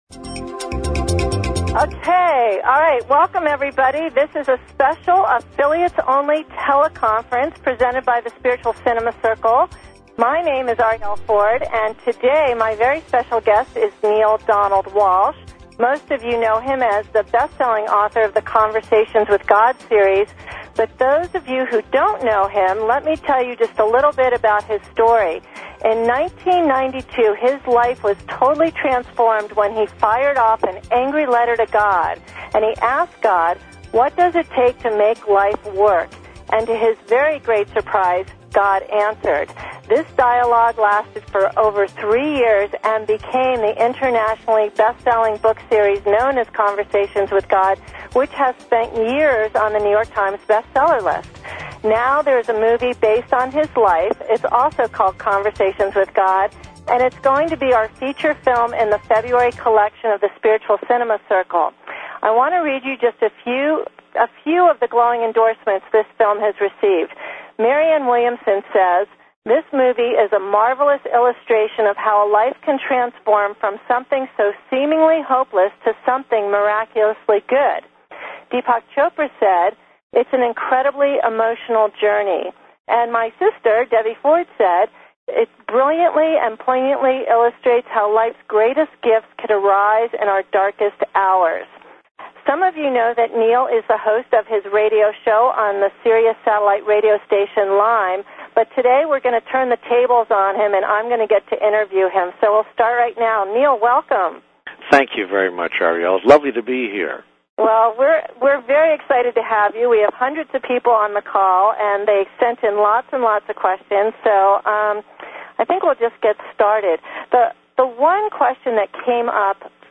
Tele-Seminar With Neale Donald Walsch